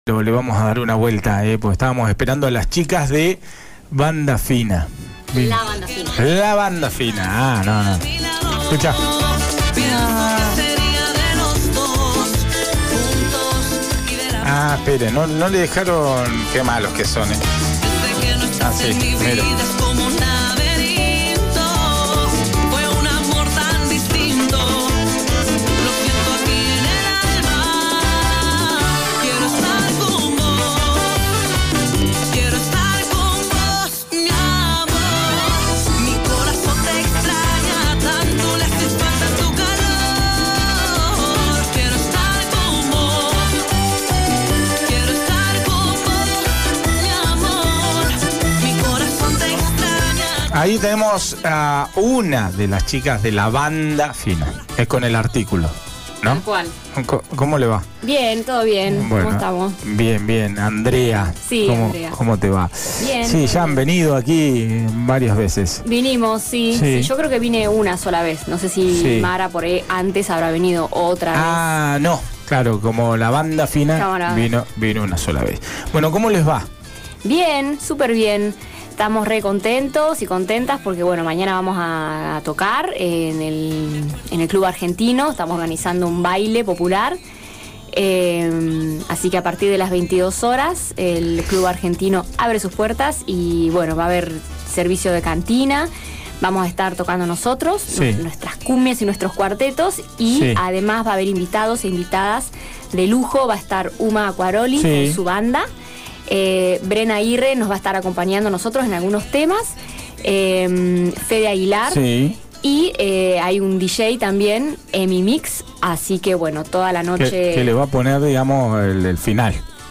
En la entrevista de esta mañana en «el mundo vive equivocado» vieron las chicas de La Banda Fina que mañana festejan de sobremanera su aniversario en el Club Argentino (Duval y A. Argentina).